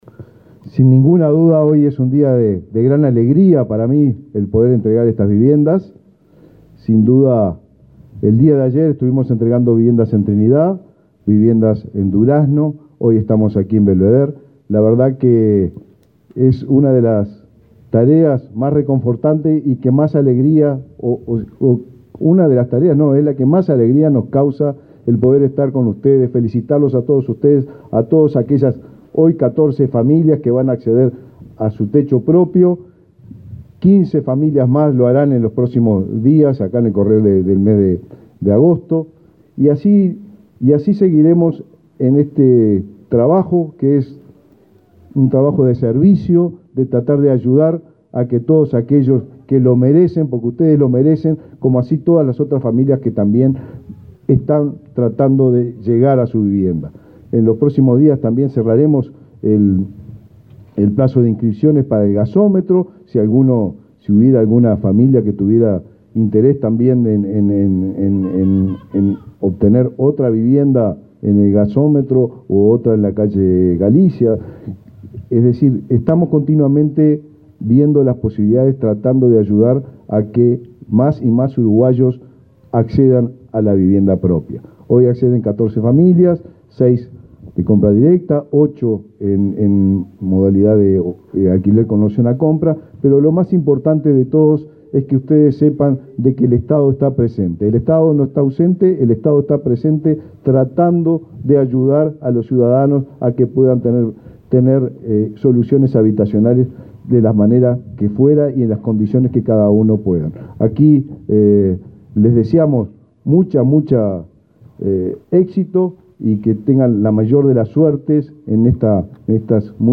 Palabras del ministro de Vivienda, Raúl Lozano
Este viernes 28, el ministro de Vivienda, Raúl Lozano, encabezó el acto de inauguración de 15 viviendas en un complejo del barrio Belvedere, en